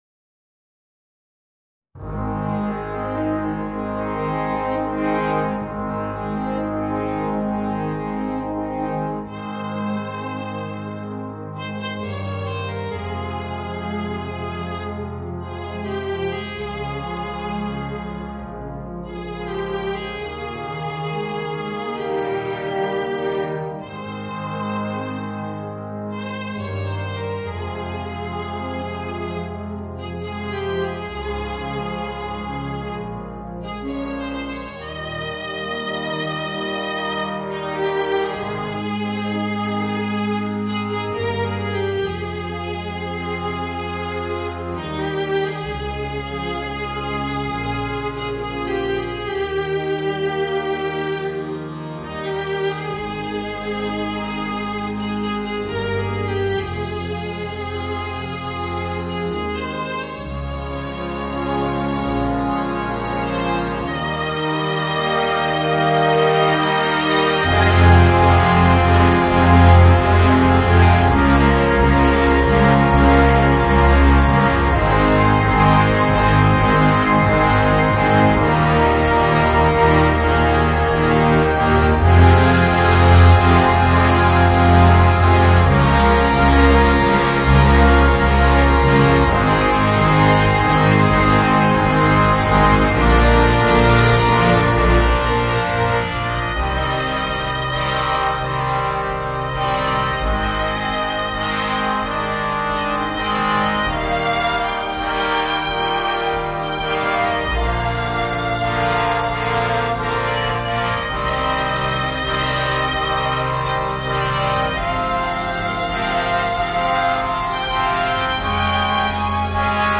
Voicing: Alto Saxophone and Concert Band